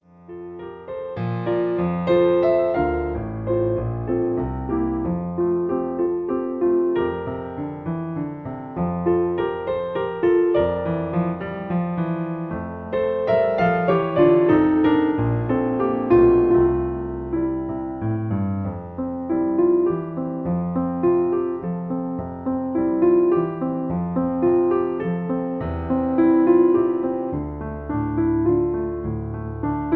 Original Key (G). Piano Accompaniment